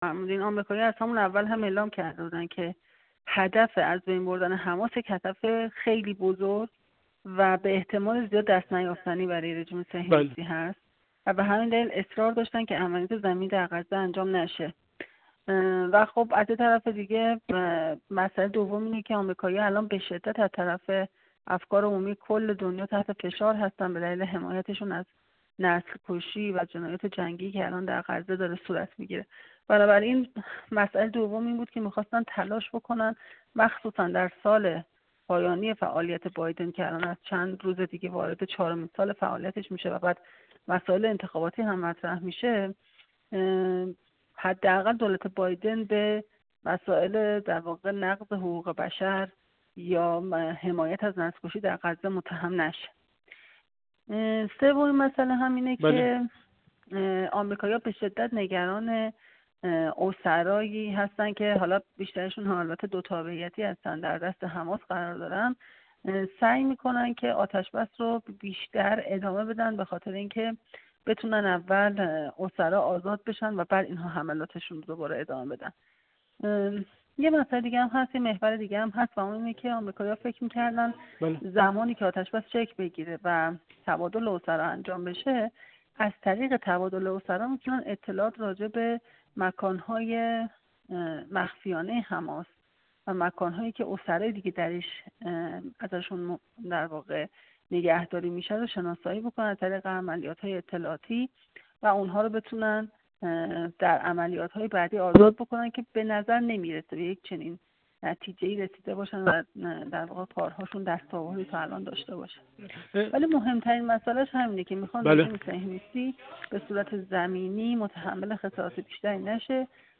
کارشناس روابط بین‌الملل
گفت‌وگو